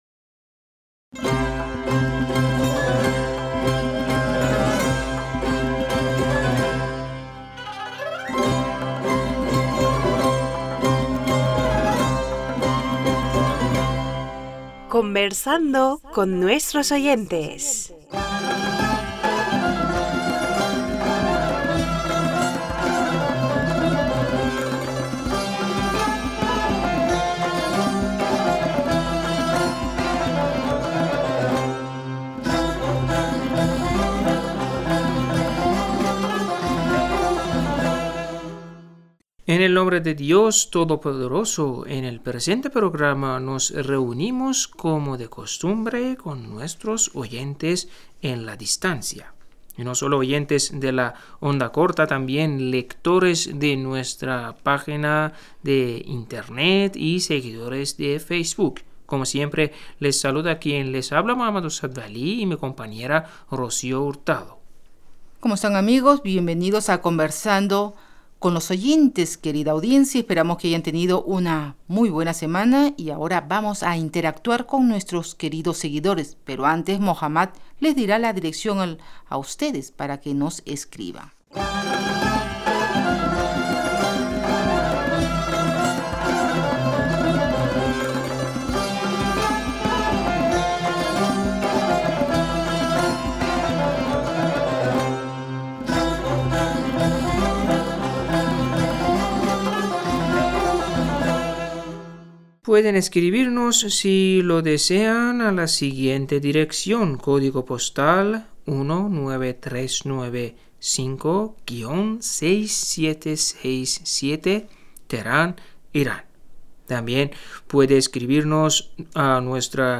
Pars Today-Entrevistas, leer cartas y correos de los oyentes de la Voz Exterior de la R.I.I. en español.